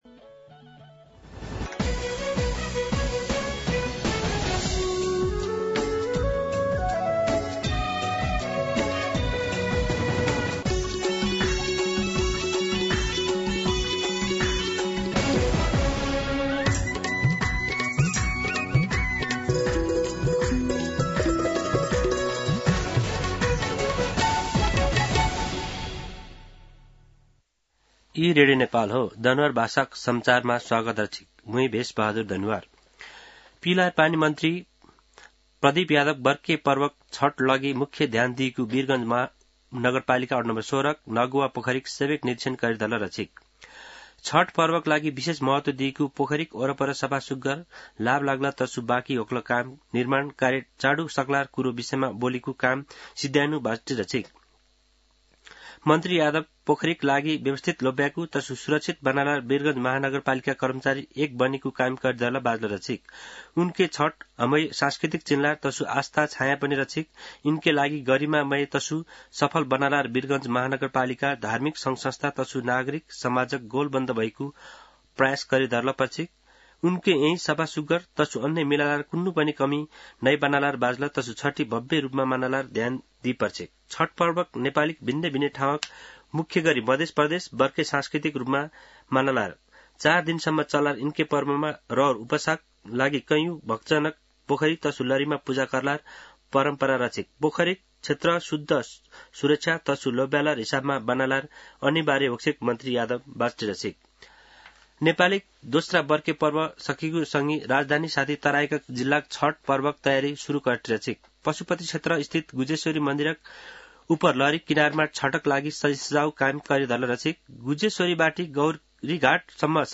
दनुवार भाषामा समाचार : २० कार्तिक , २०८१